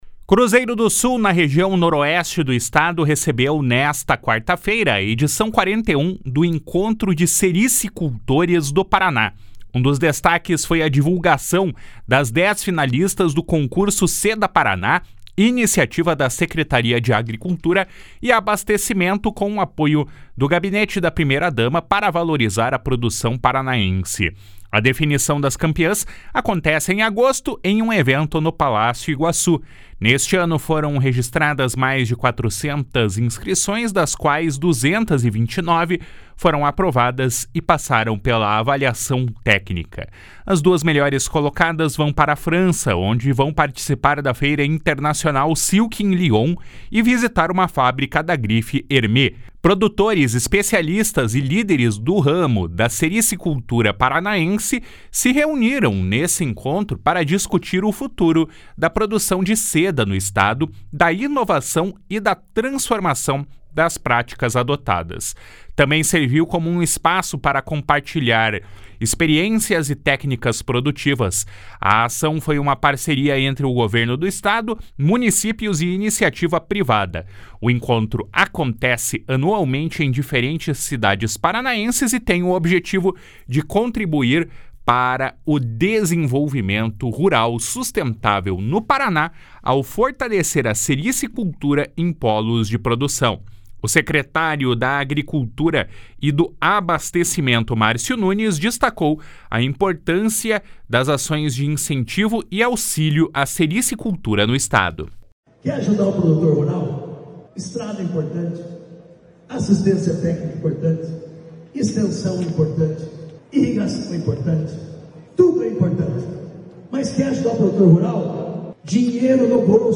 // SONORA MÁRCIO NUNES //